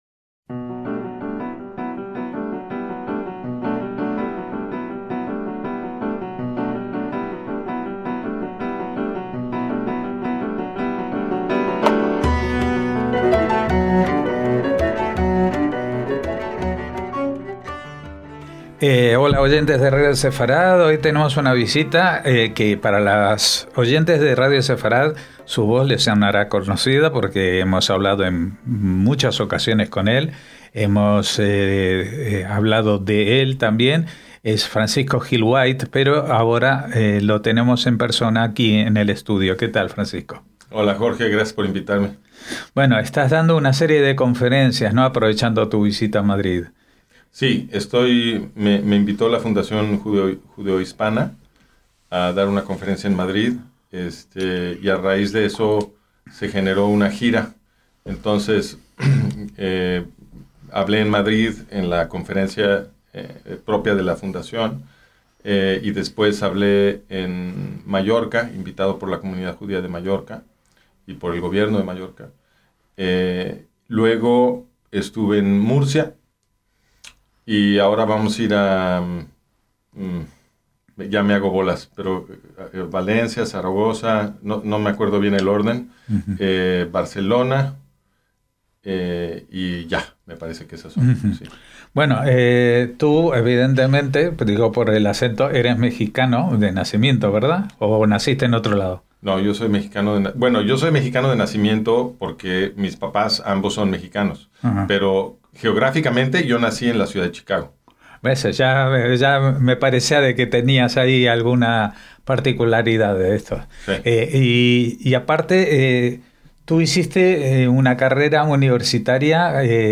Charlamos extensamente con él para conocer sus raíces y su evolución personal, desde la composición musical y la antropología, a su sorprendente visión sobre el judaísmo, el antisemitismo y el conflicto árabe-israelí, entre otros muchos temas.